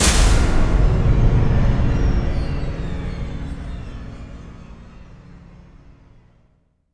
LC IMP SLAM 8B.WAV